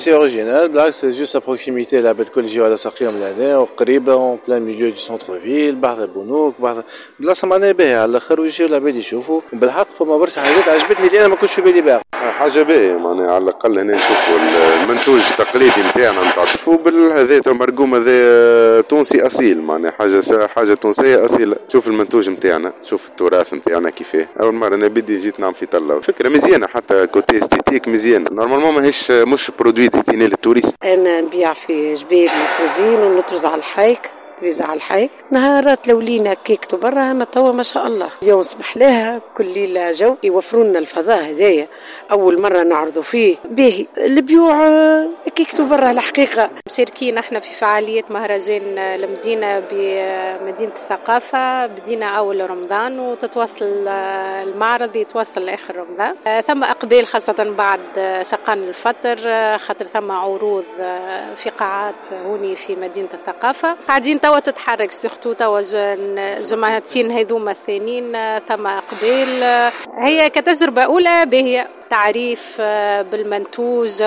من جهة أخرى، استبشر المواطنون بهذه المبادرة التي مكنتهم من اكتشاف منتوجات تقليدية، وفق تصريحهم